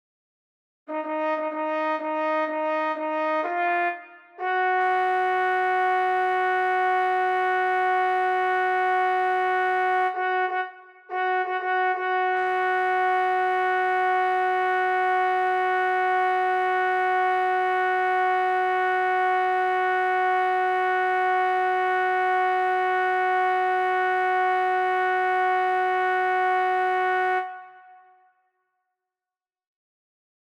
Key written in: F# Major
Type: Barbershop
Each recording below is single part only.